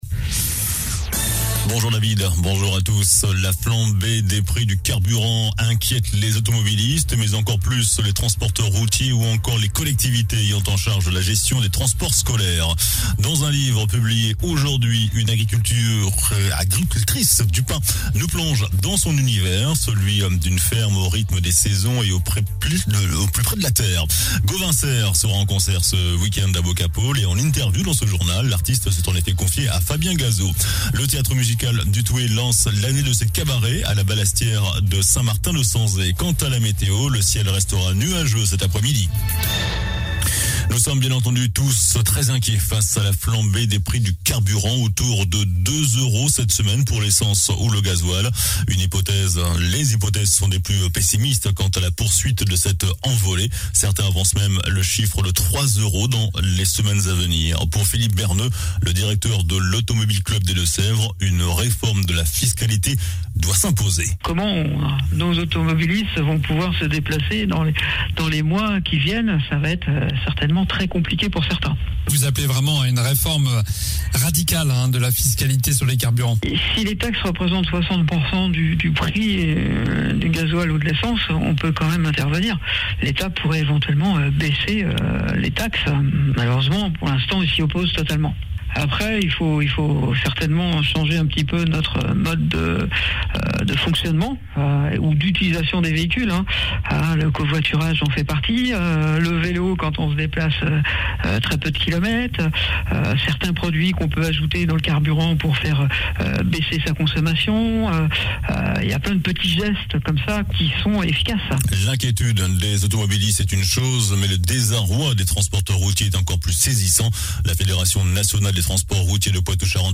JOURNAL DU JEUDI 10 MARS ( MIDI )